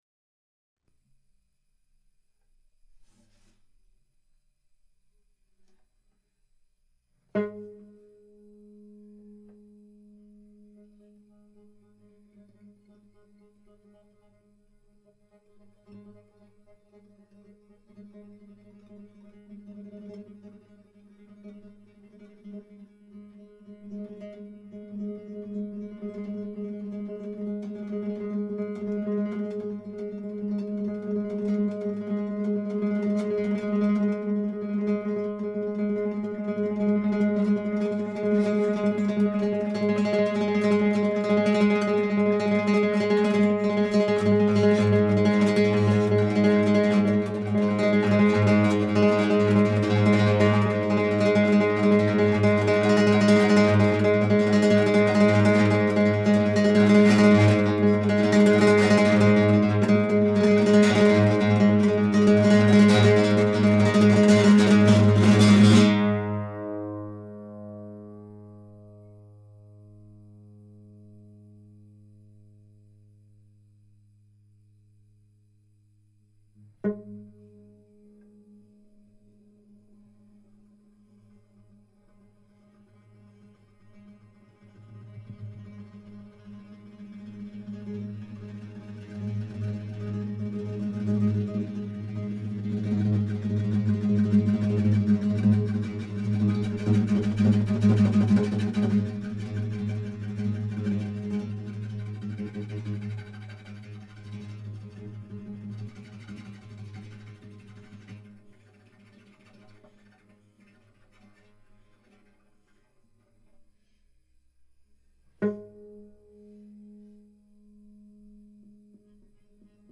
concert recording
guitar